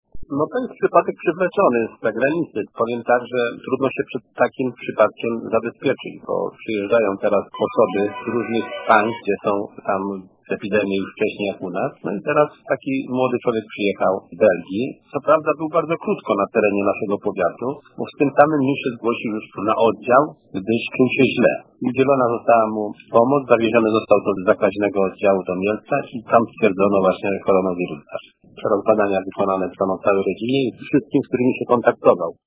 Mówi starosta stalowowolski Janusz Zarzeczny